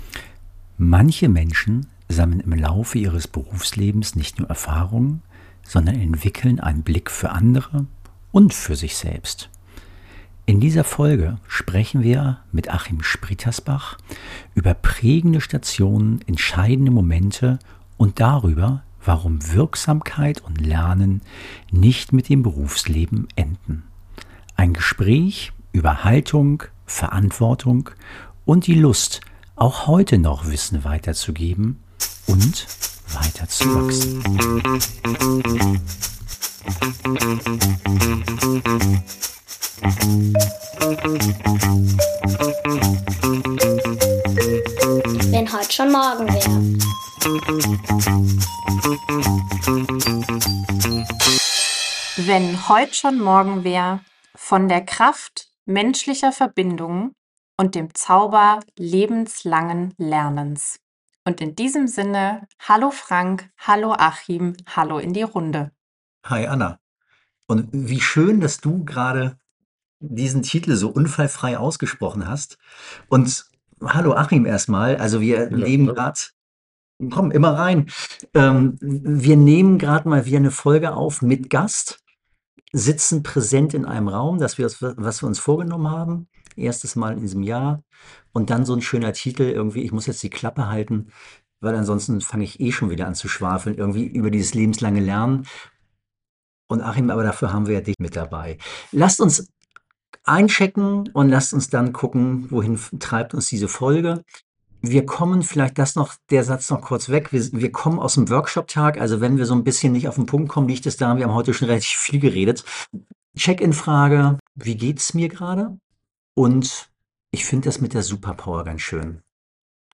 Was dabei herauskommt, wenn sich drei Menschen mit gleichen Herzensthemen zufällig im gleichen Workshop befinden? Ein wundervolles Spontan-Podcast-Gespräch wie in dieser Folge!